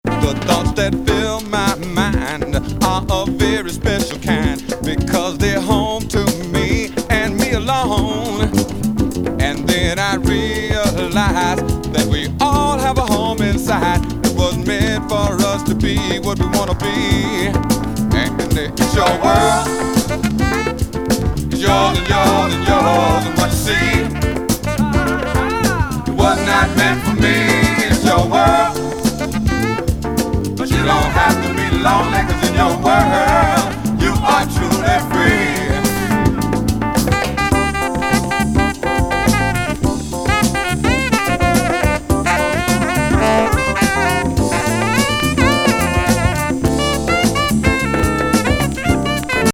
RARE GROOVE CLASSIC!